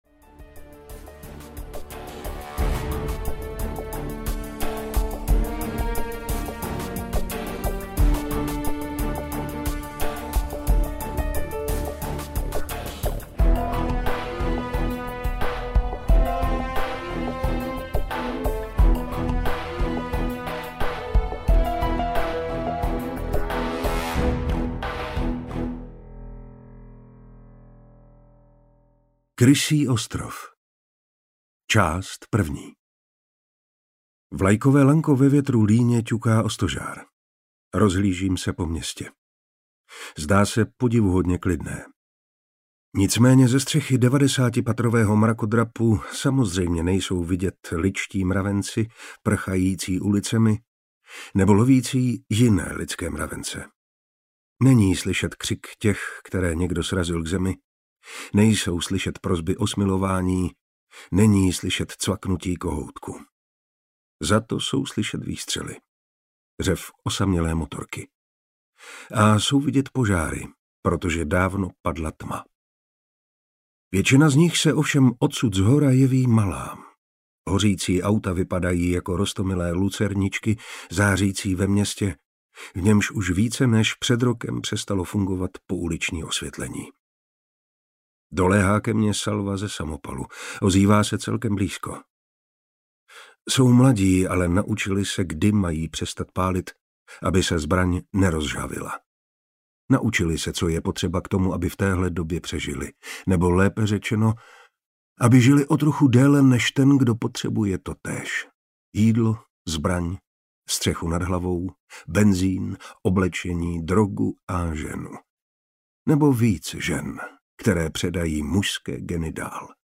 Krysí ostrov a jiné povídky audiokniha
Ukázka z knihy